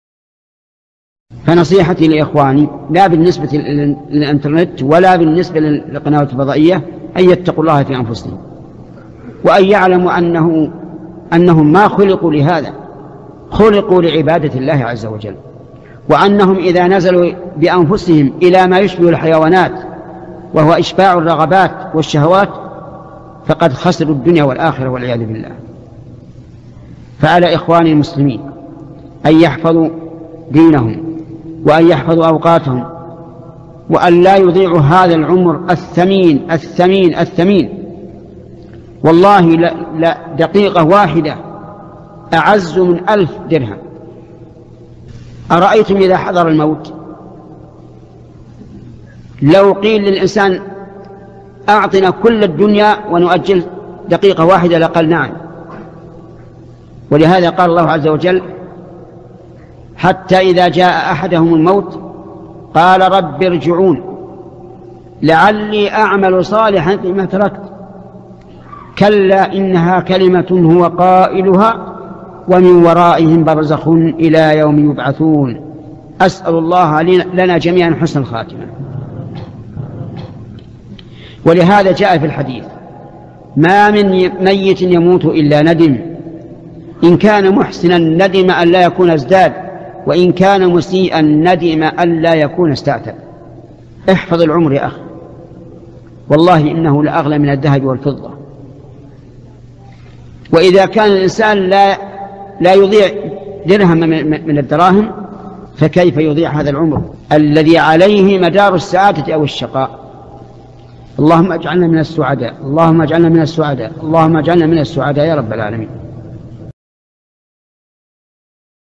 الشيخ: محمد بن صالح العثيمين القسم: من مواعظ أهل العلم